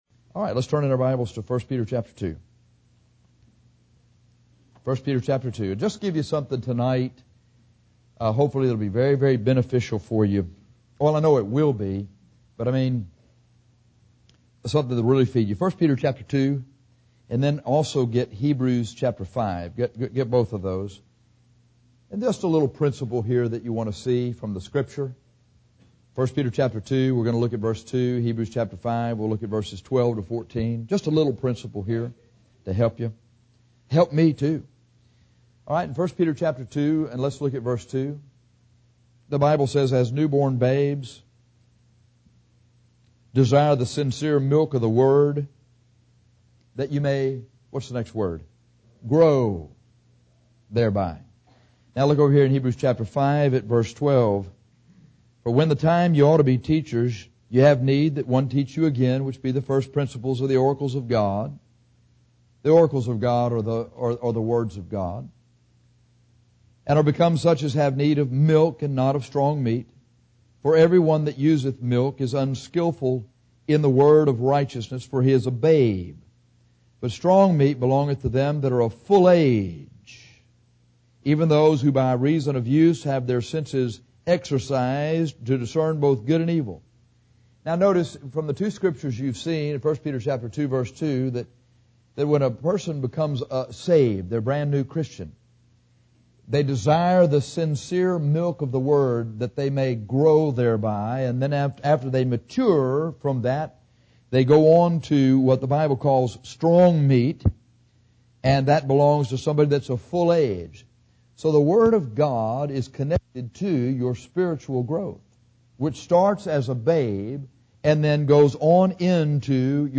The companion passage for this sermon is Heb 5:12. The theme is how to know that you are growing spiritually.